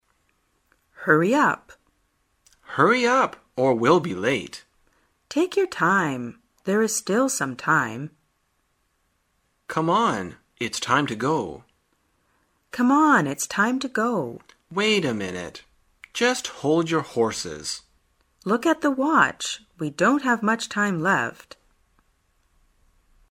旅游口语情景对话 第270天:如何表达催促